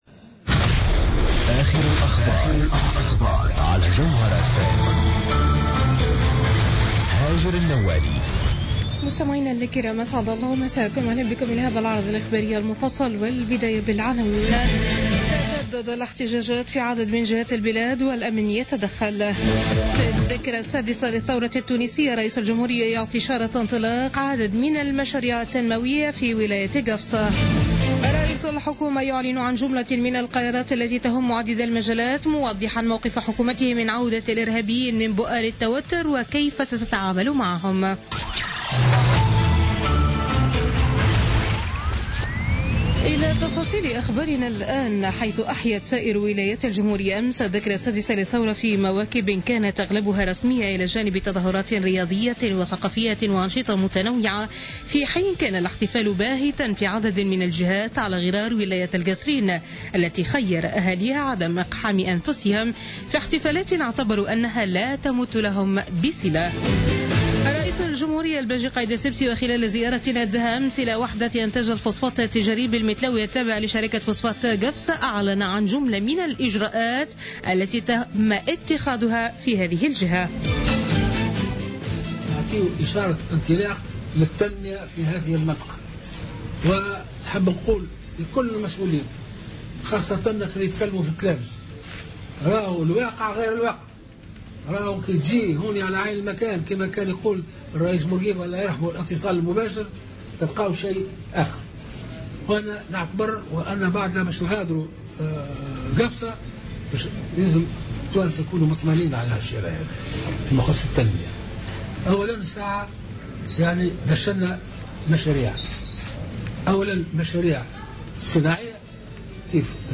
نشرة أخبار منتصف الليل ليوم الأحد 15 جانفي 2017